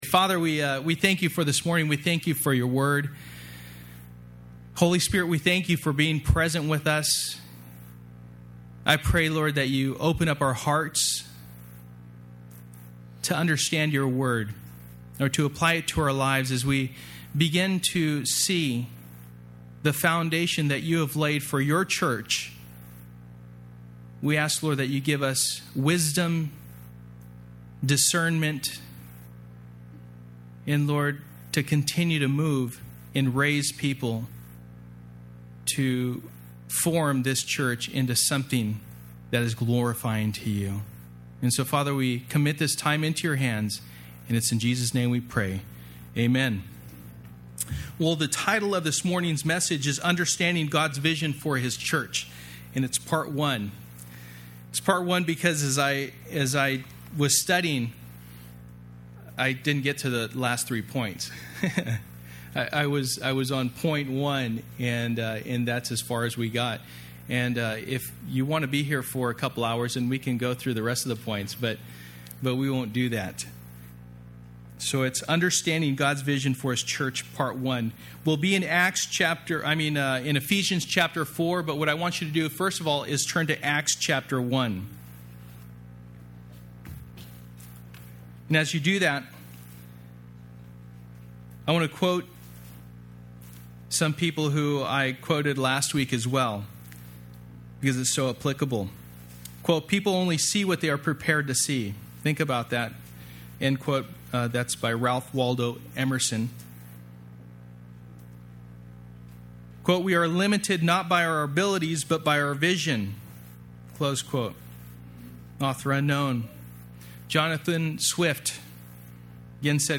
Passage: Ephesians 4:11-16 Service: Sunday Morning